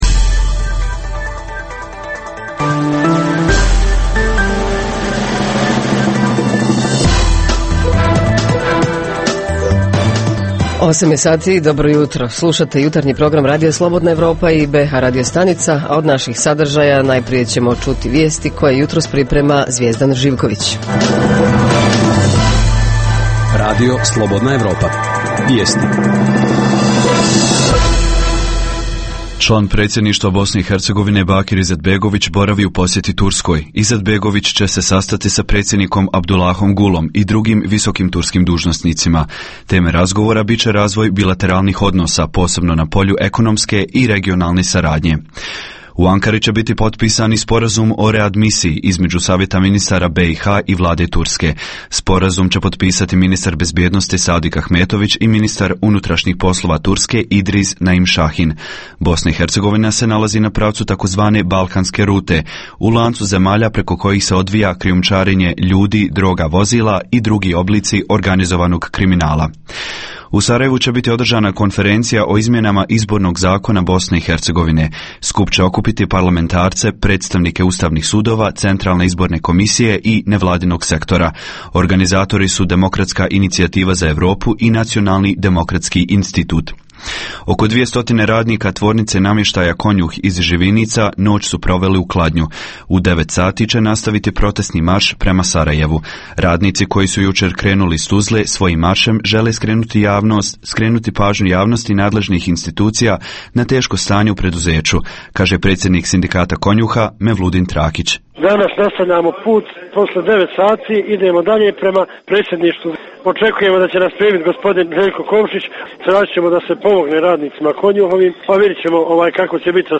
U jutarnjoj emisiji poslušajte: - Sarajevo: Prenosimo dio atmosfere i izjave sa gala premijere filma "U zemlji krvi i meda", koja je održana u sarajevskoj Zetri pred oko 5000 gledalaca.